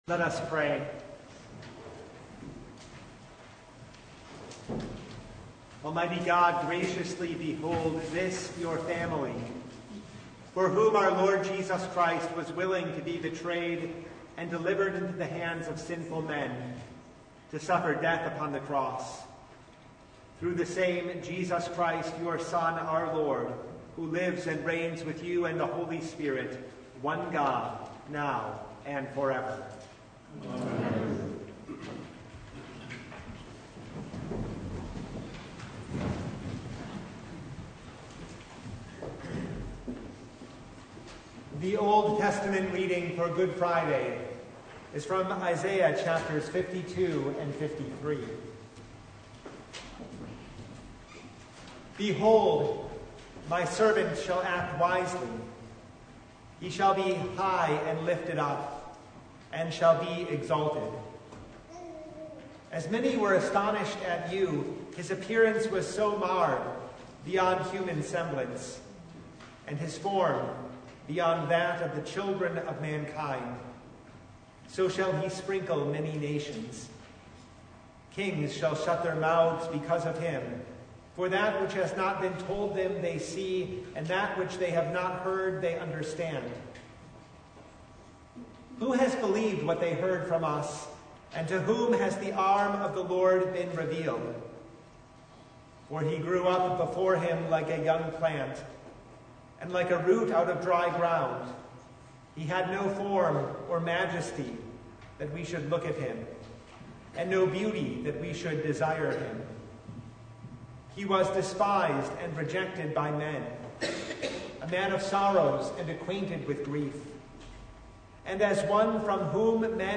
Good Friday Chief Service (2026)
Topics: Full Service